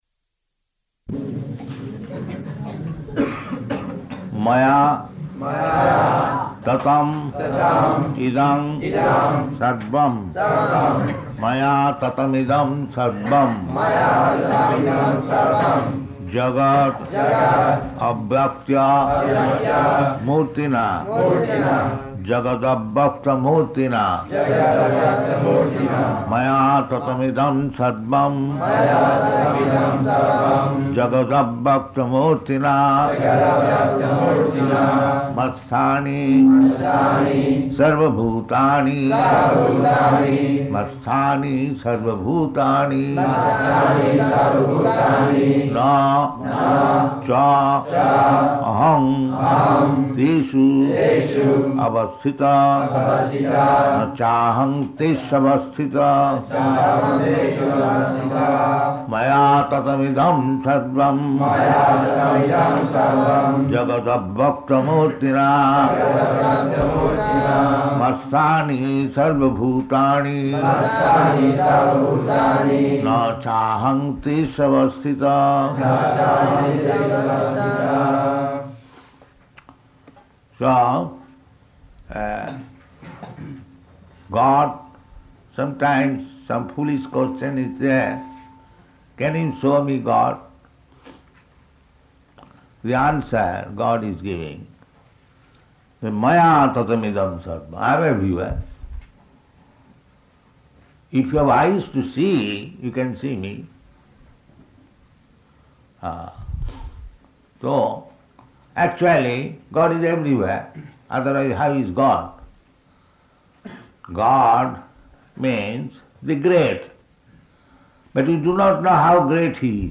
Location: Melbourne
[leads devotees in chanting]